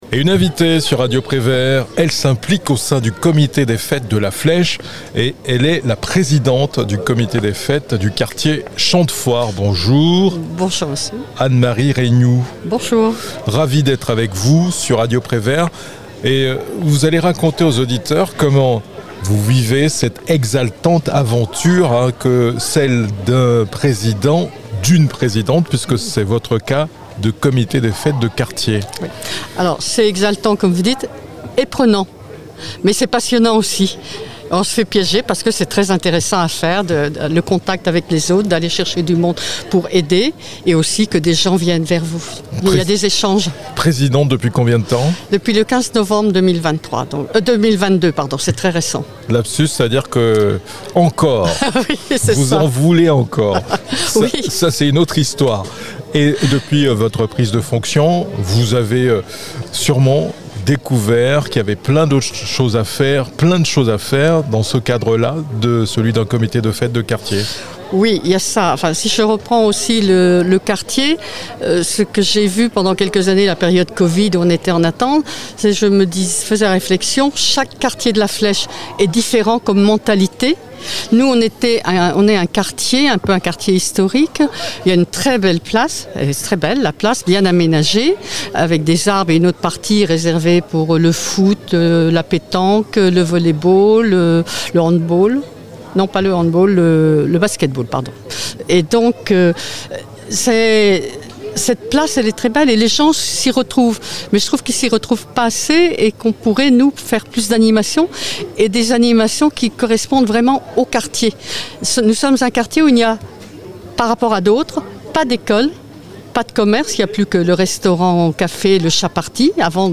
La Flèche : entretien